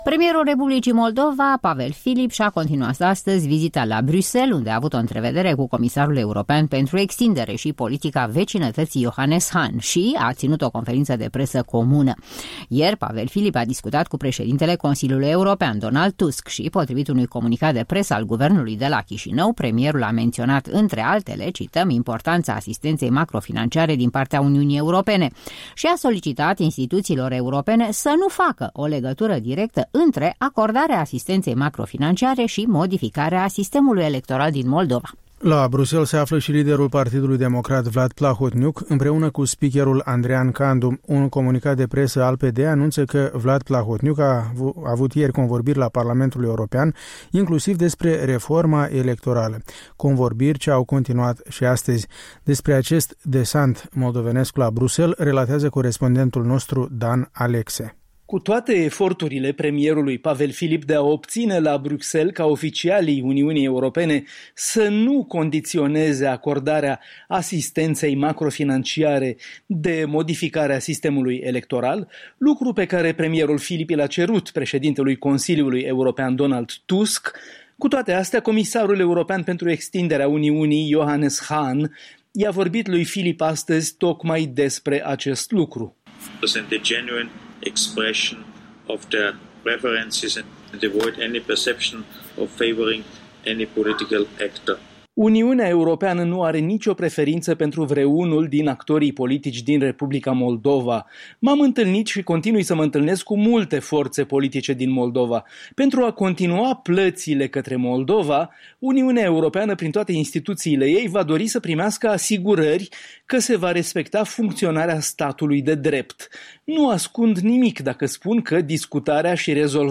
Despre acest „desant” moldovenesc la Bruxelles, relateaza corespondentul nostru la fața locului: